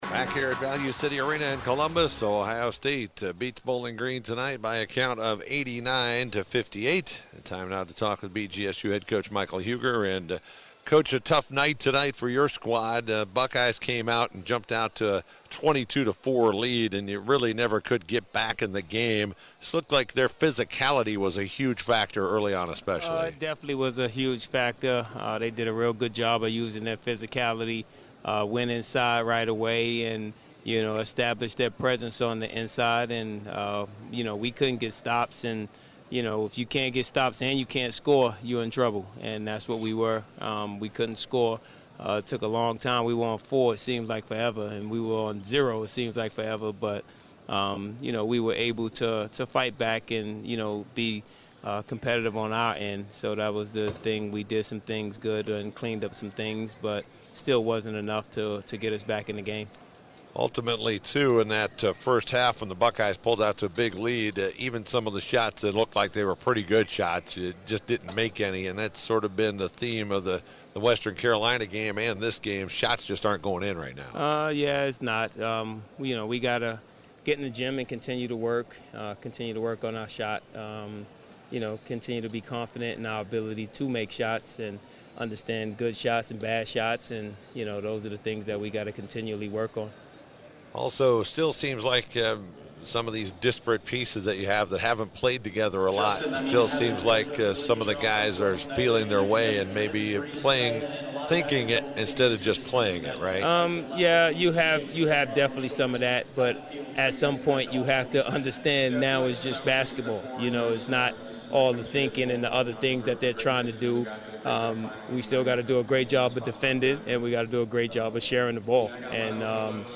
Full Postgame Interview